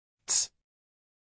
uk_phonetics_sound_cats.mp3